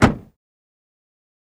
BMW Car Door Close